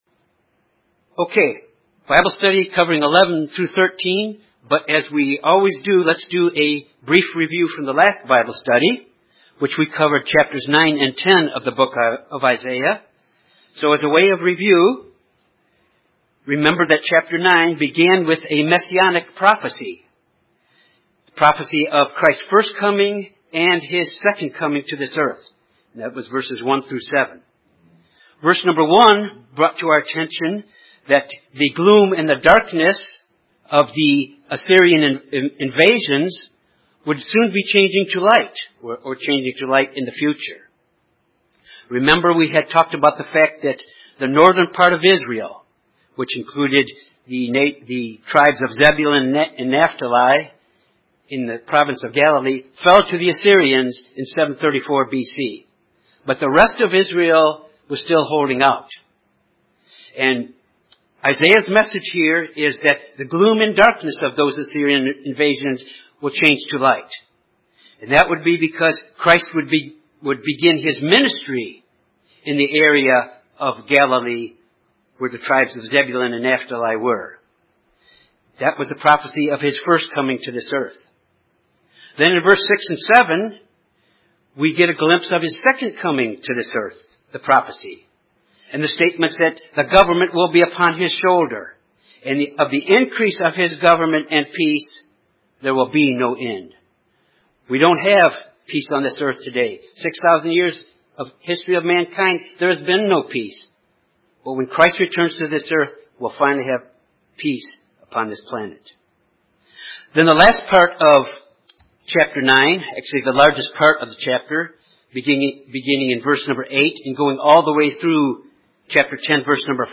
This Bible study of Isaiah chapters 11 through 13 covers prophecies of the Messiah and the Day of the Lord.